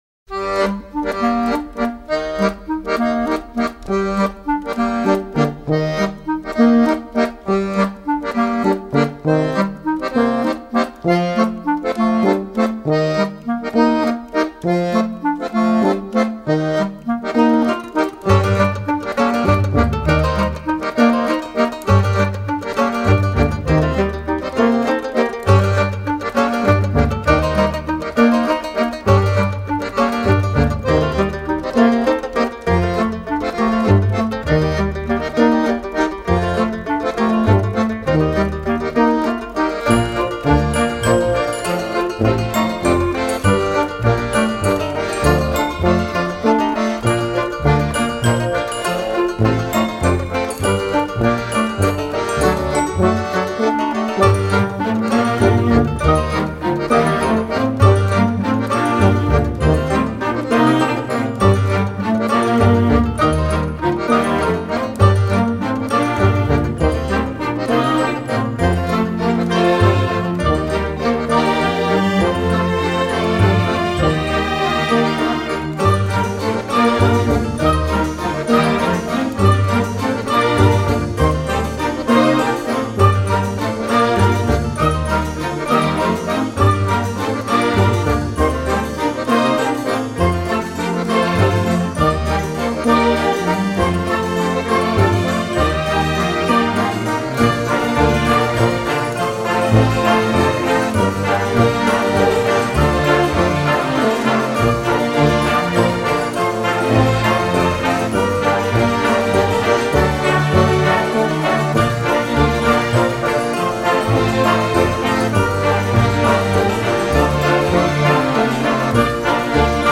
音乐类型:Original Soundtrack
层层叠叠的钢琴、小提琴、手风琴、鼓声、口琴
主旋律带出一次次变奏，旋律就这般悠然荡漾。